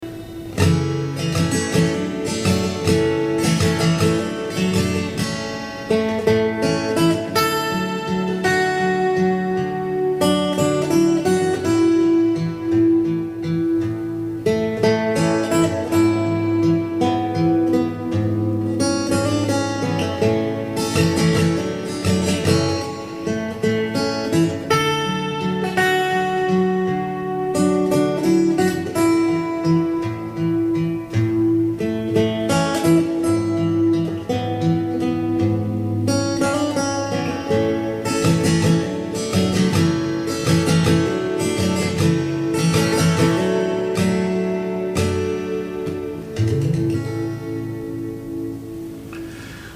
Audio Clip from the Tutorial
Capo 2nd Fret - 3/4 Time
on_top_of_old_smokey_fingerstyle.mp3